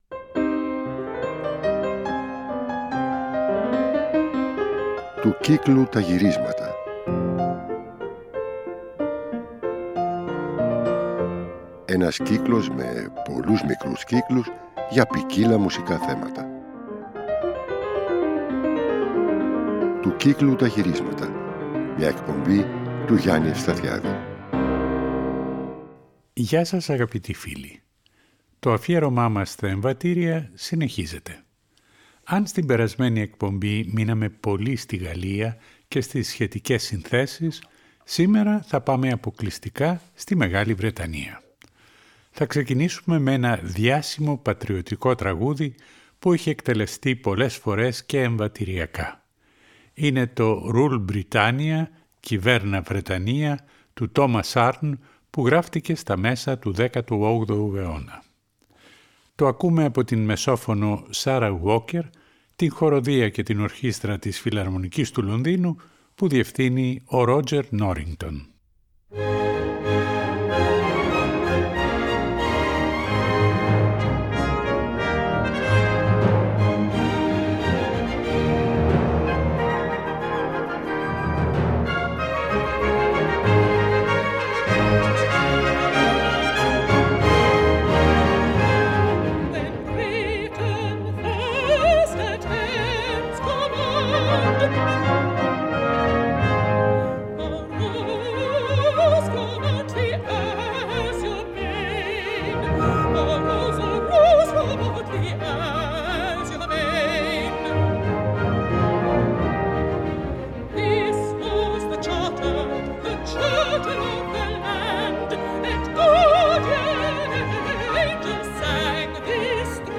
Ο κύκλος για τα εμβατήρια συνεχίζεται.